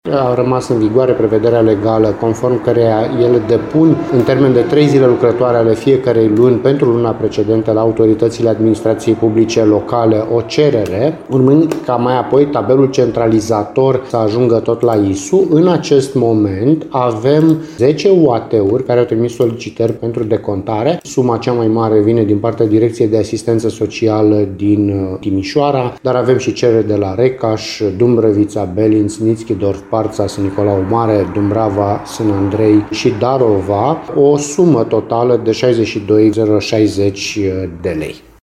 Suma pe care o va achita statul pentru persoanele fizice din Timiș care au adăpostit refugiați se ridică la 62.000 de lei, spune subprefectul Sorin Ionescu: